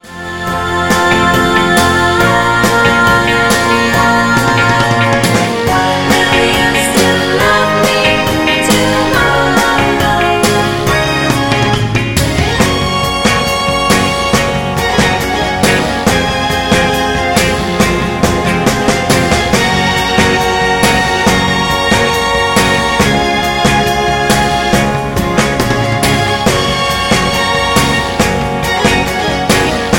Backing track files: Oldies (1113)